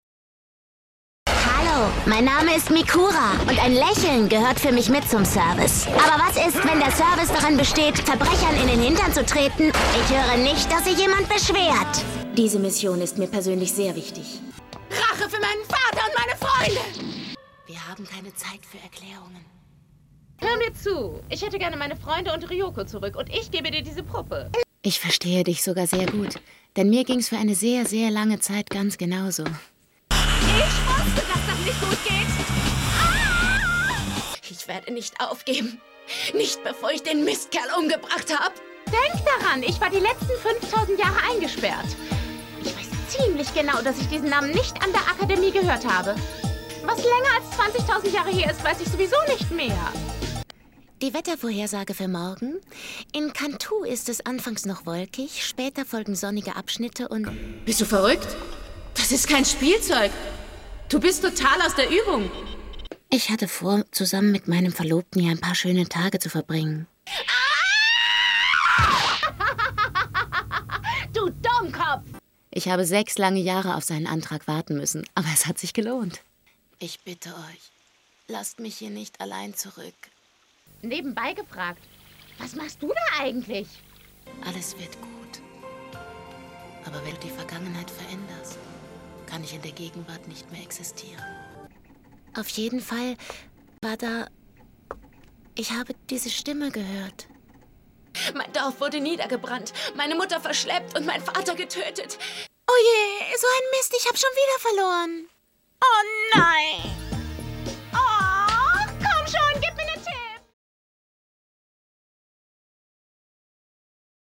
facettenreich,dynamisch, kräftig, schrill, kindlich, lieblich, verführerisch, warm, Schauspielerin, Sängerin
Sprechprobe: Sonstiges (Muttersprache):